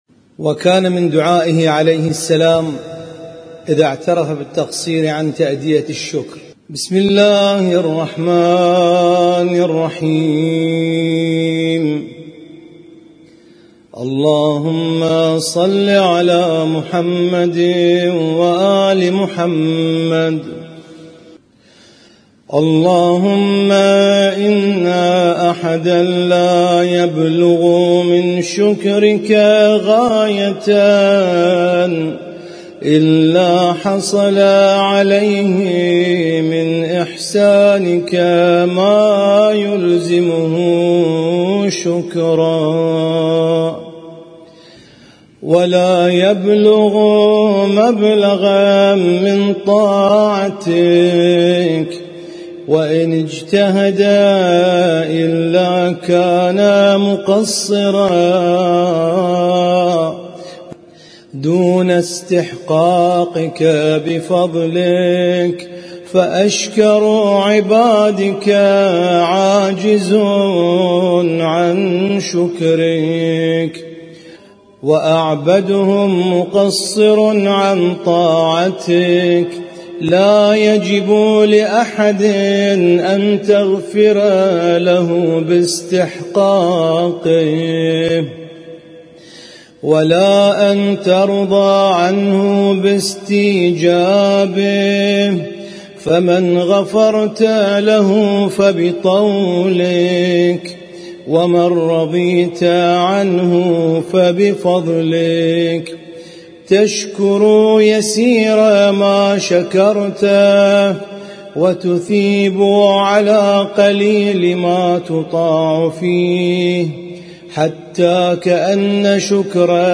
اسم التصنيف: المـكتبة الصــوتيه >> الصحيفة السجادية >> الادعية السجادية